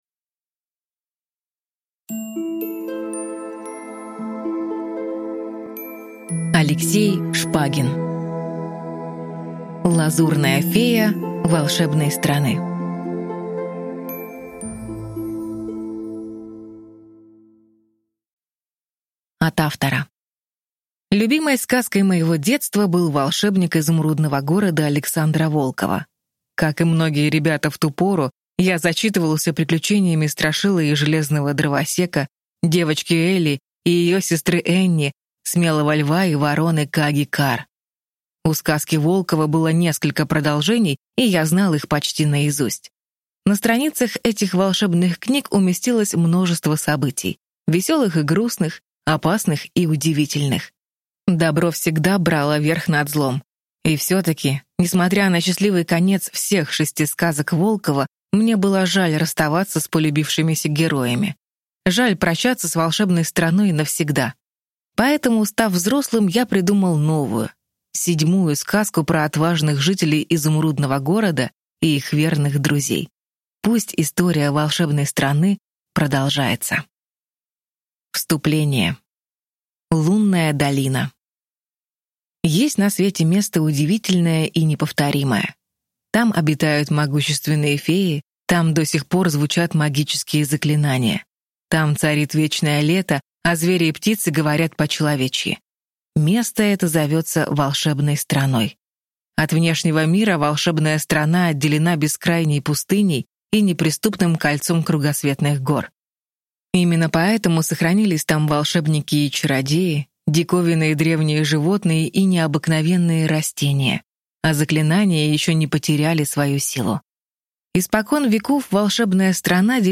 Аудиокнига Лазурная фея Волшебной страны | Библиотека аудиокниг